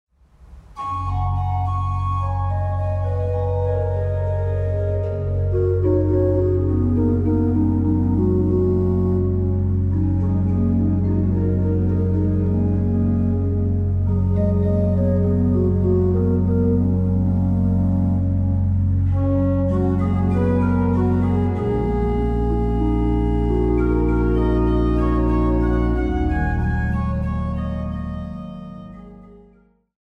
dziecięcy katolicki zespół religijny z Nowego Sącza.